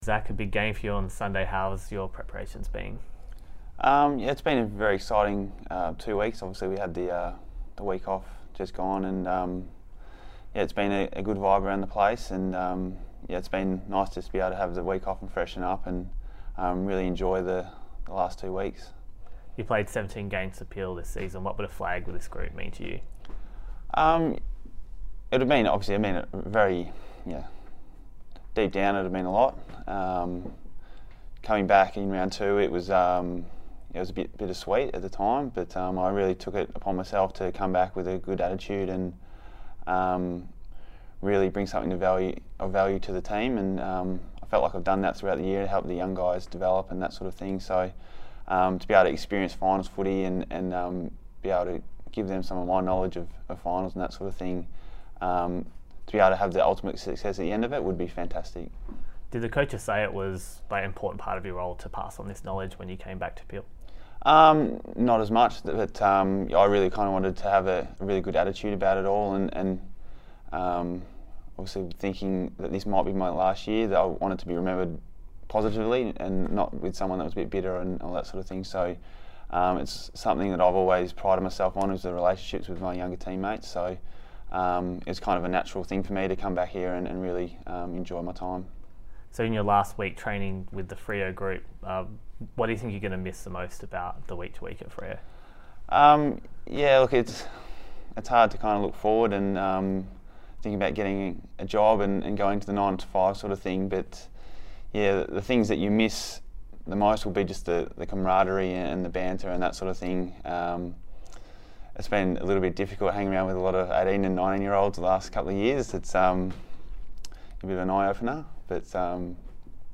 Zac Dawson chats to Docker TV ahead of the WAFL Grand Final.